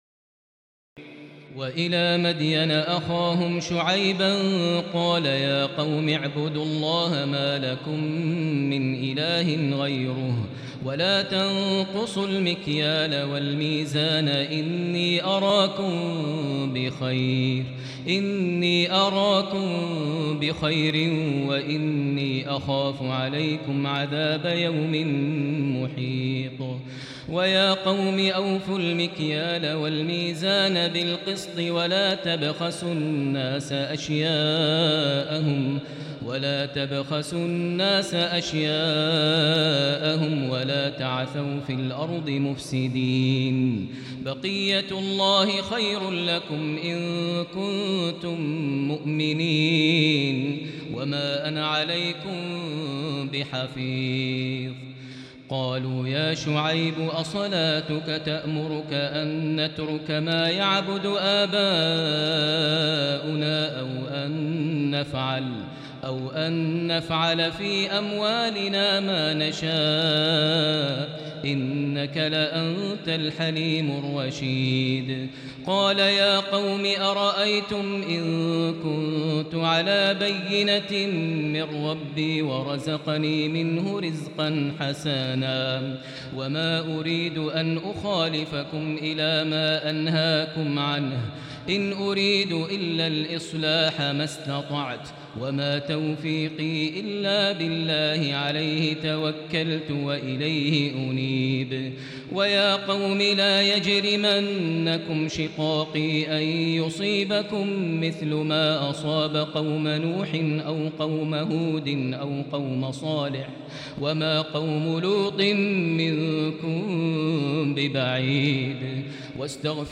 تراويح الليلة الحادية عشر رمضان 1438هـ من سورتي هود (84-123) و يوسف (1-53) Taraweeh 11 st night Ramadan 1438H from Surah Hud and Yusuf > تراويح الحرم المكي عام 1438 🕋 > التراويح - تلاوات الحرمين